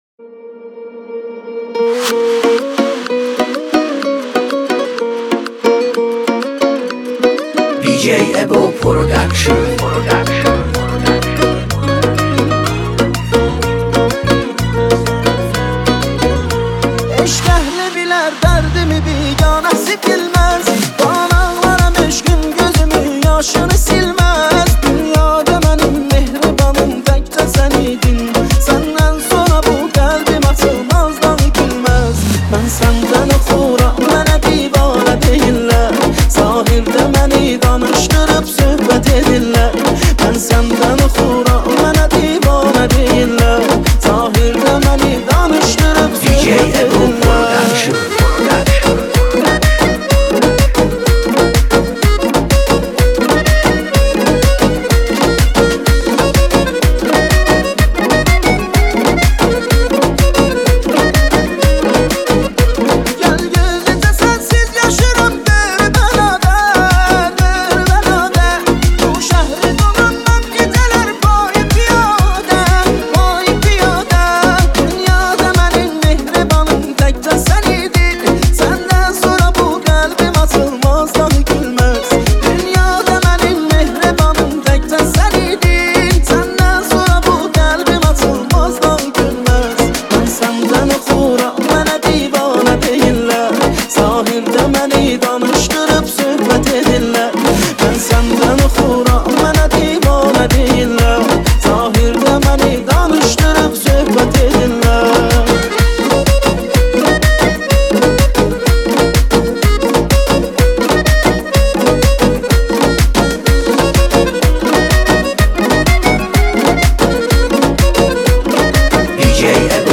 ترکی